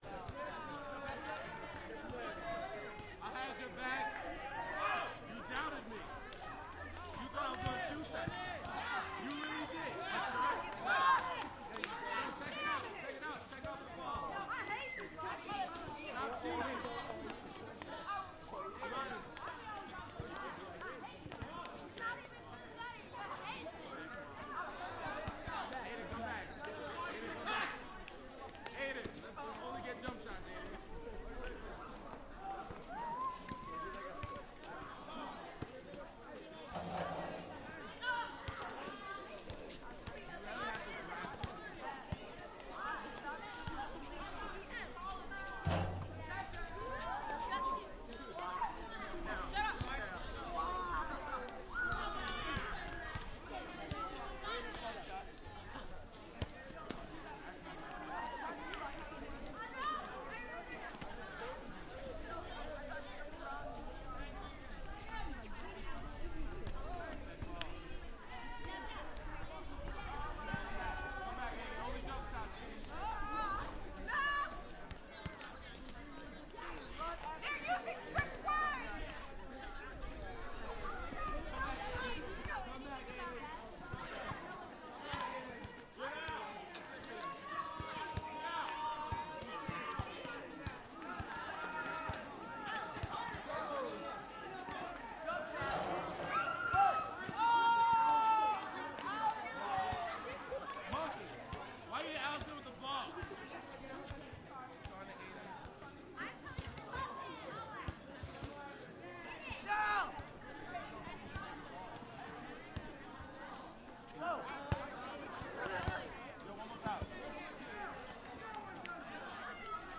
Kids_Playing_(1).mp3